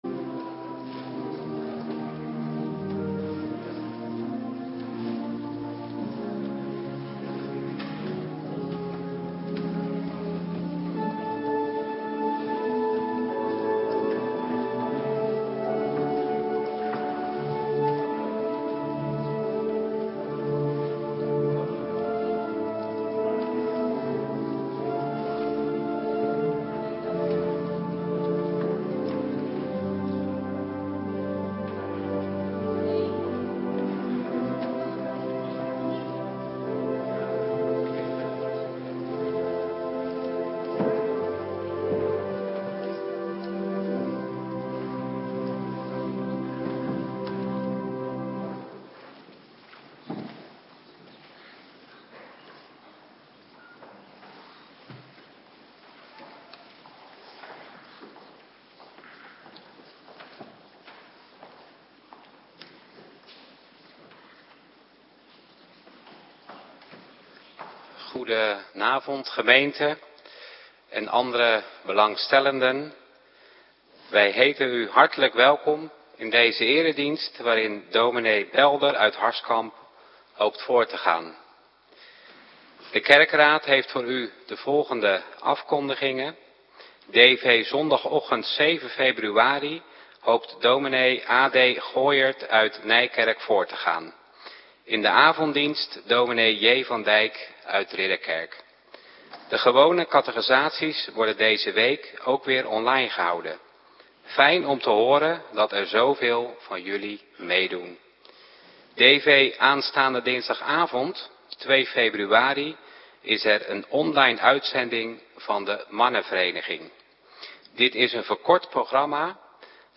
Avonddienst - Cluster 3
Locatie: Hervormde Gemeente Waarder